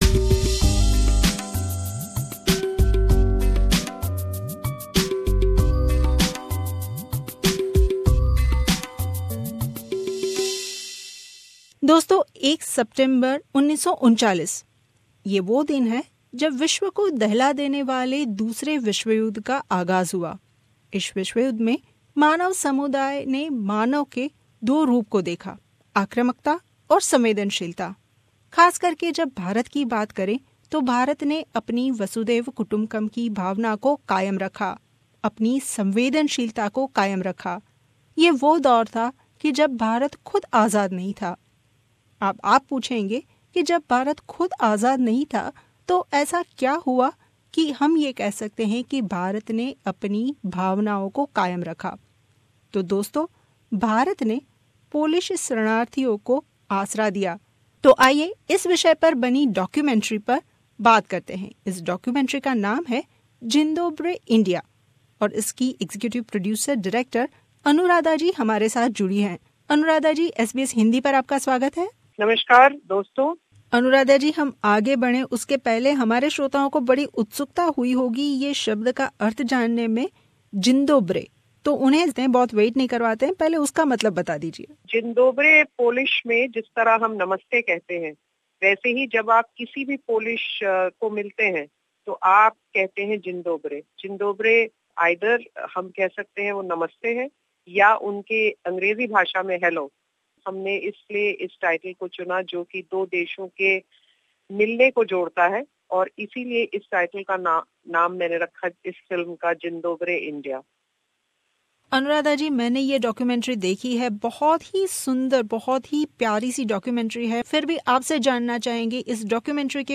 खास भेटवार्ता